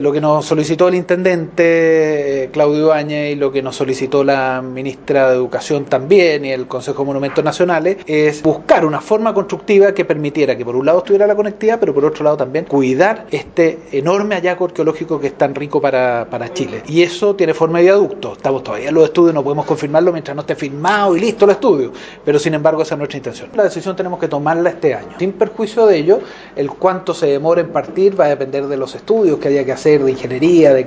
ministro_obras_p__blicas___alberto_undurraga.mp3